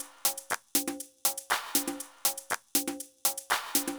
Drumloop 120bpm 05-B.wav